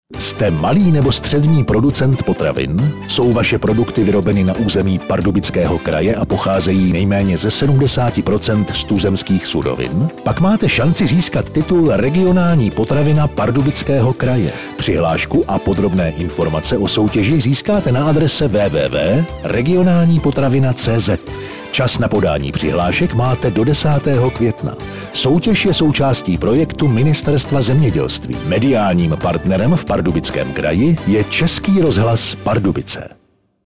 Upoutávku v Českém rozhlase Pardubice na soutěž Regionální potravina Pardubického kraje 2013 najdete Z D E.